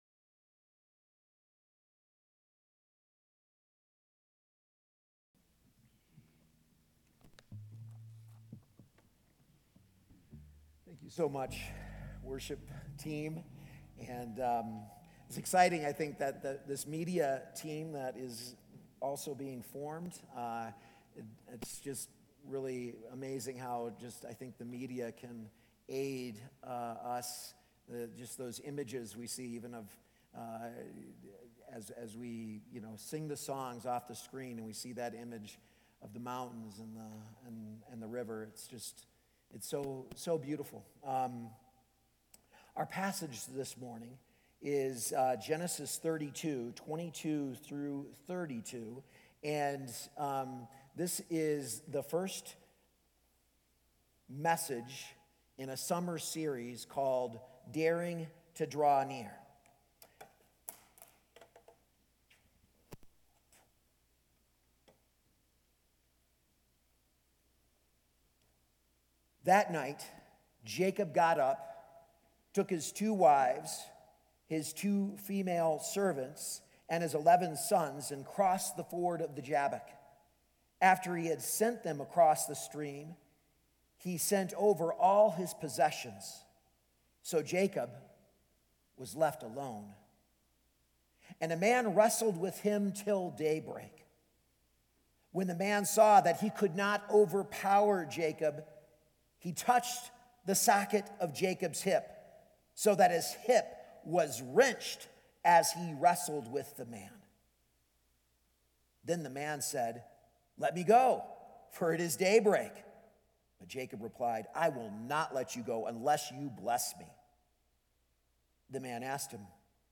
A message from the series "Daring to Draw Near."